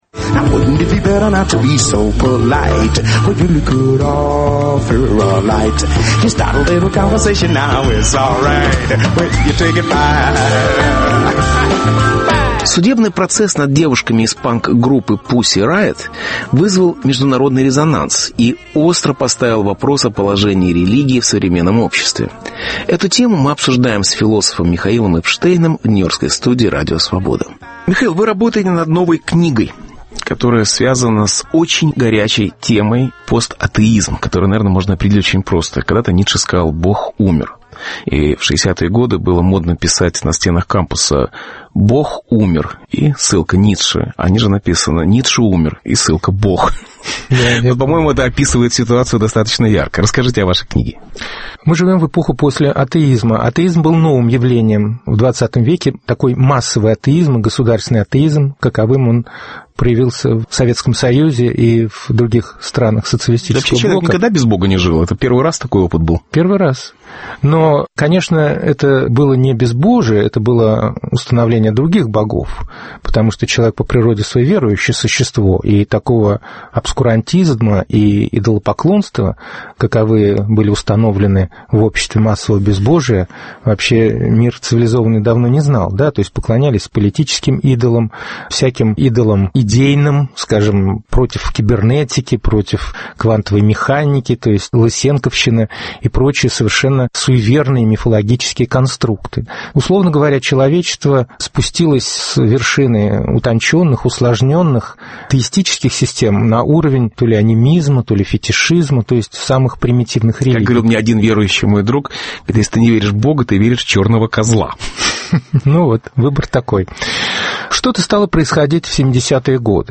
Бедная религия (Беседа с Михаилом Эпштейном)